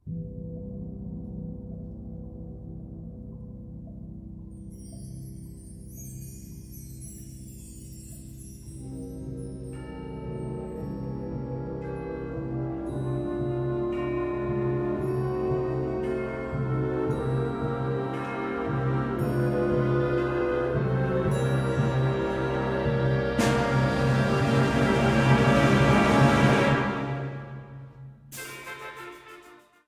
Catégorie Harmonie/Fanfare/Brass-band
Sous-catégorie Musique de concert
Instrumentation Ha (orchestre d'harmonie)
Cette pièce rapide et ludique ravira vos élèves.
Très nombreux effets de tambour et de maillet obligatoires.